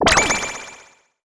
SFX item_get_armor_2.wav